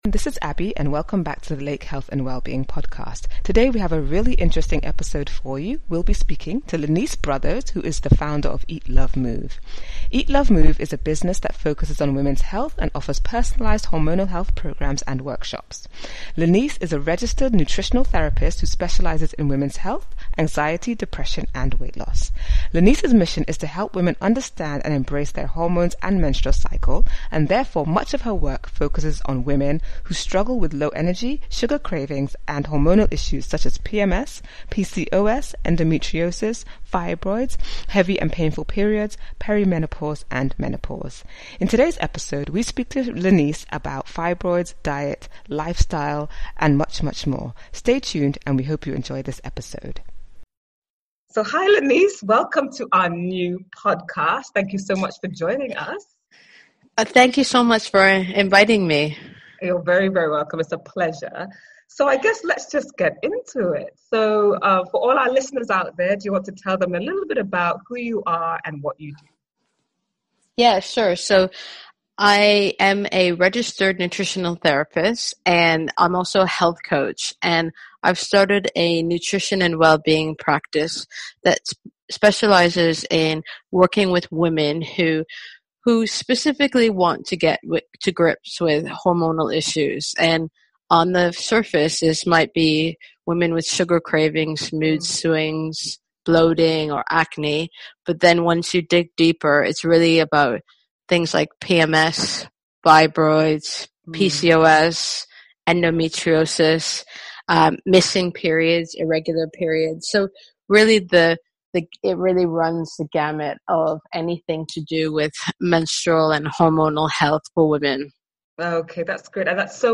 In this week’s episode we have a really interesting and insightful conversation